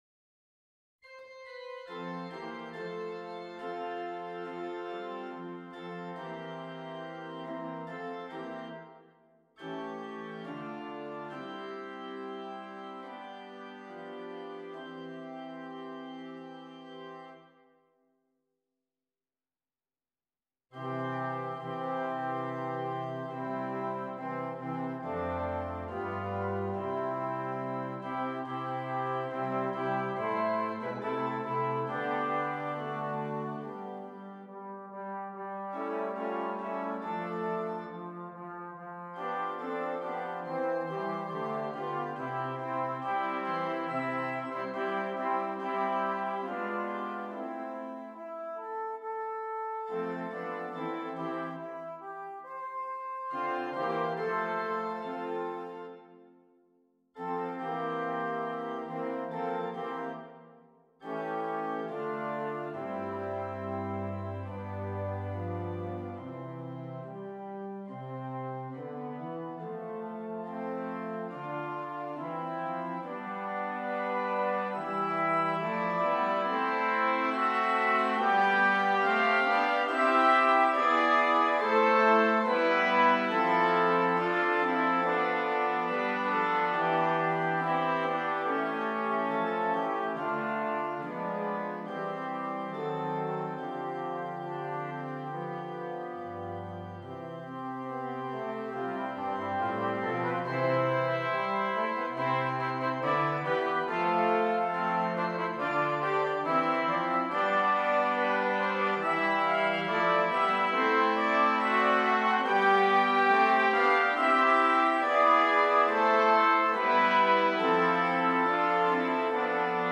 Brass Quartet and Organ